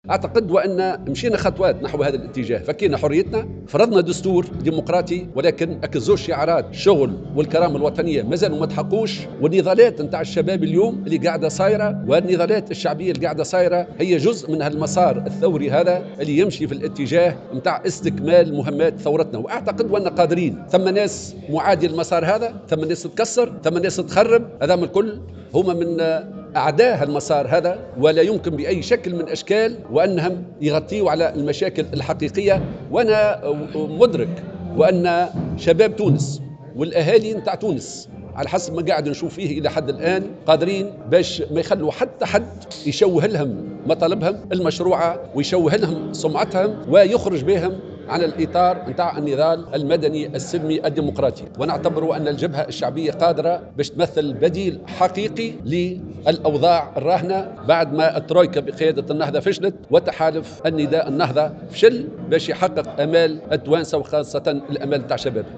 وفي رده على اتهامات بشأن تأجيج جبهته للأوضاع في تونس، أكد الهمامي في تصريحات لمراسل "الجوهرة أف أم" على هامش تظاهرة حول ذكرى تأسيس حزب العمال، أكد أن الفقر وفشل منظومة الحكم في تونس هما وراء تأجيج الأوضاع، معتبرا أن شعارات الثورة لم تتحقق بعد.